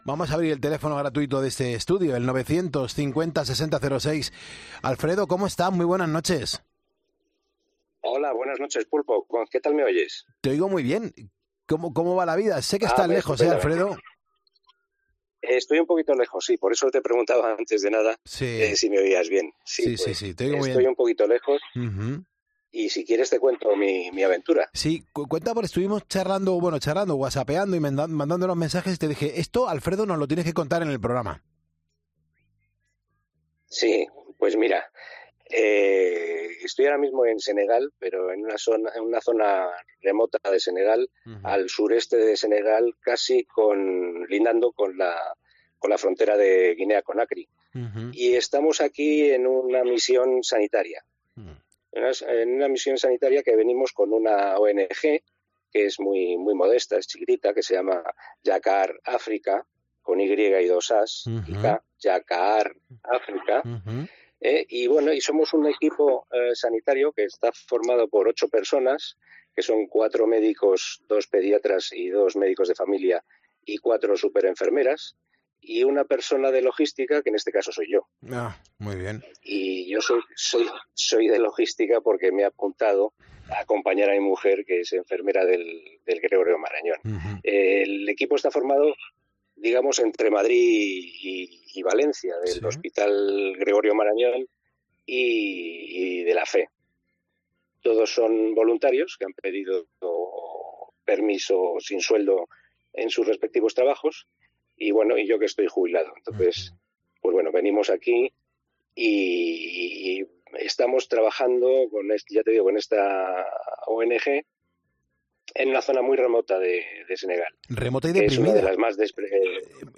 Poniendo las Calles tiene oyentes por todo el mundo y una prueba de ello es la llamada